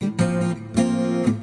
引入流行电吉他曲调
描述：一个电吉他介绍即兴演奏，在它上面放一些鼓和贝司！
标签： 前奏 蓝调 声学 岩石 棕榈 RIFF 吉他 失真 金属 键盘 静音 电动 和弦
声道立体声